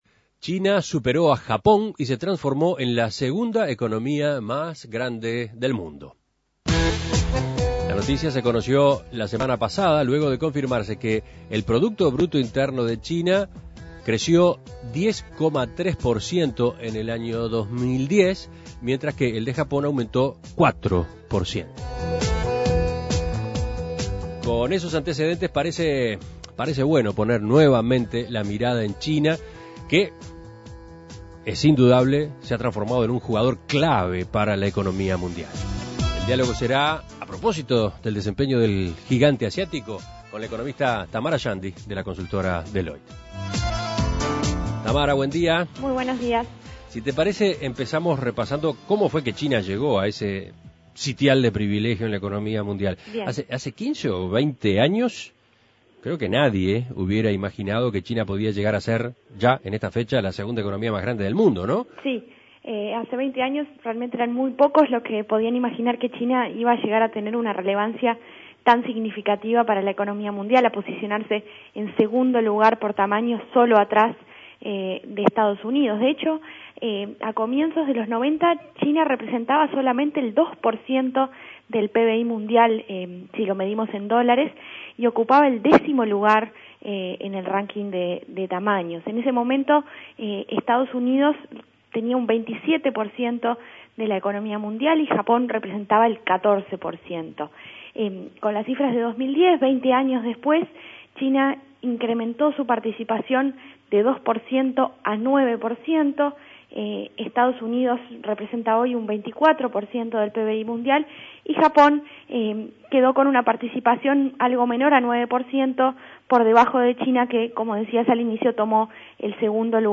Análisis Económico China creció 10,3% en 2010 y se transformó en la segunda economía más grande del mundo